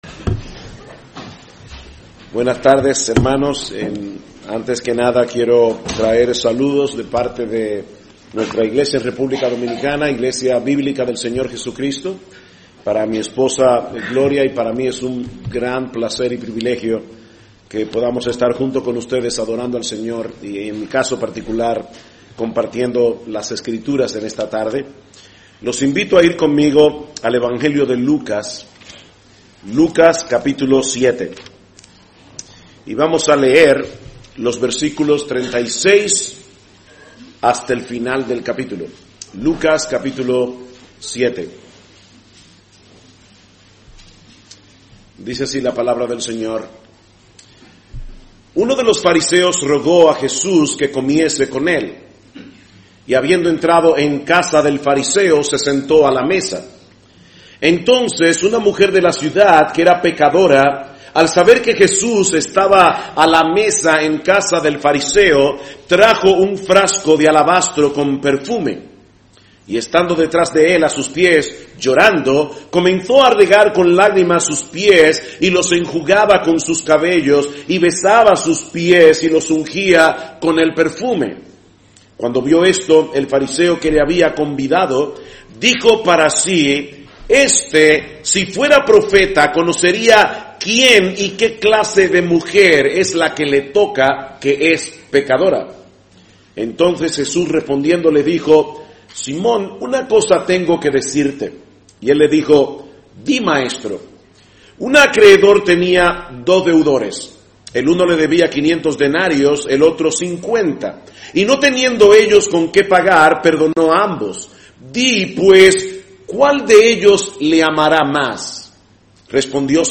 Listado Últimos Sermones